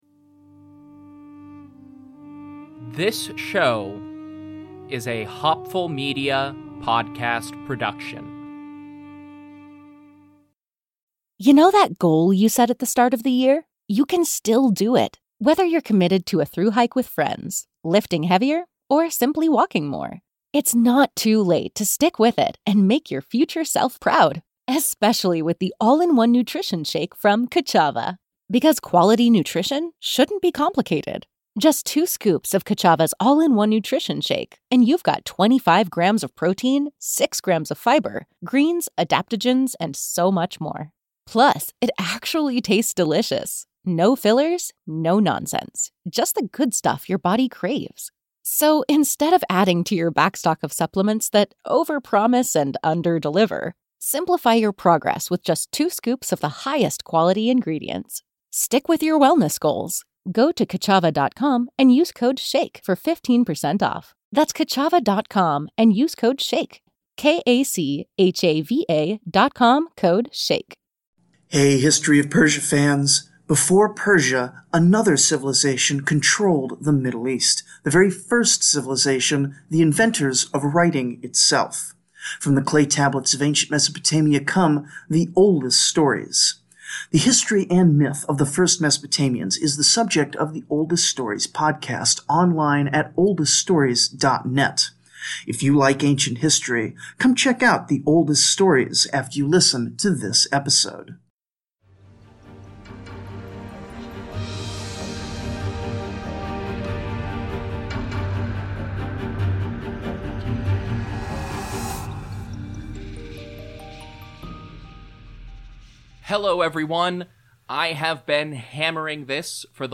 Intelligent Speech Online 2026 is almost here, and if I haven't yet sold you on attending the conference on February 28, 2026 (starting at 9am US Eastern Time), you might be interested in my presentation from last year's event.